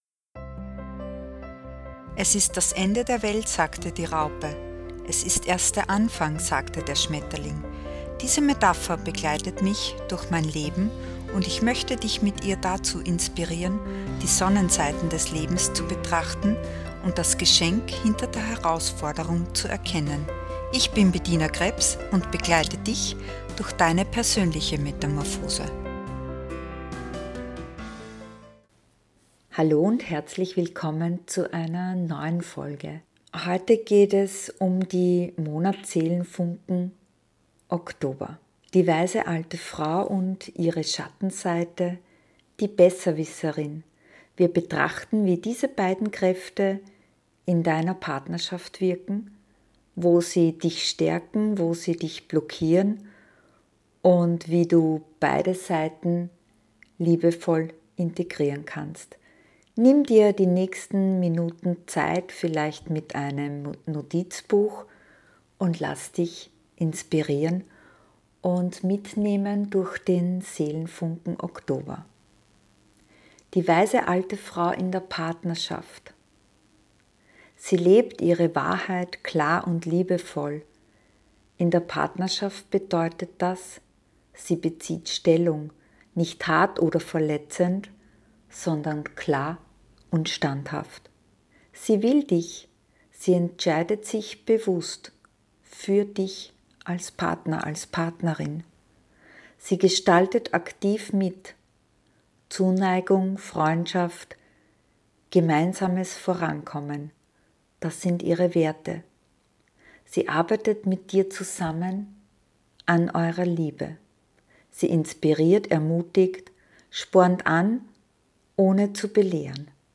In dieser 20-minütigen Folge begegnen wir der weisen alten Frau — und ihrer ungeheilten Seite, der Besserwisserin. Mit einer geführten Meditation, konkreten Alltagsübungen und einer kraftvollen Affirmation für deine Mitte.